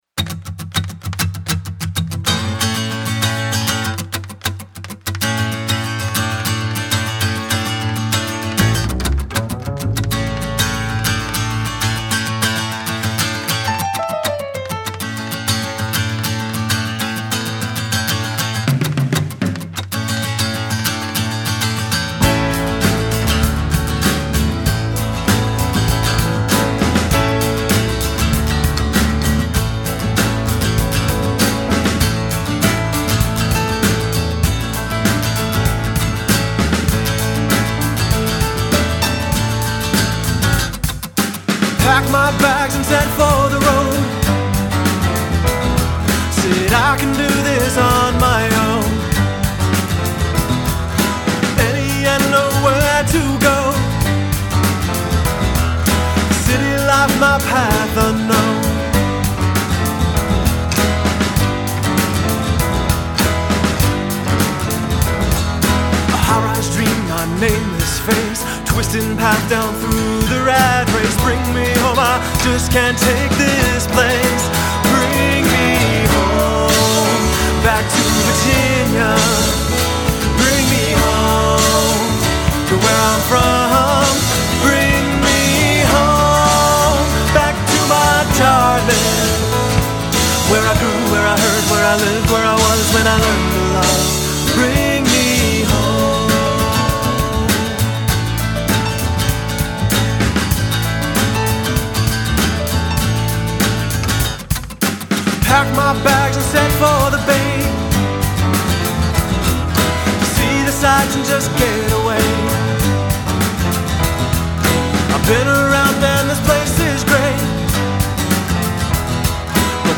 Guitar, Vocals
Drums
Piano, Organ
Upright Bass